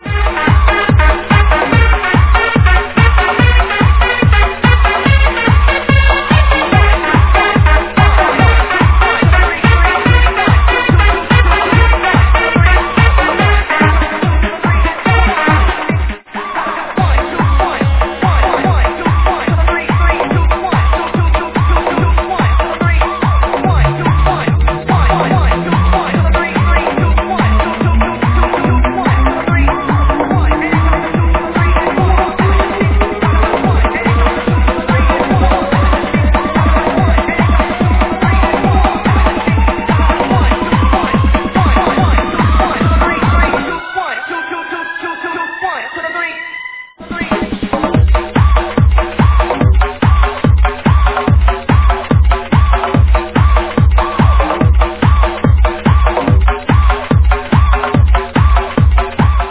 Klubb House Track - Need ID
Please help ID this klubb track, the attached sample contains three different parts of the same tune.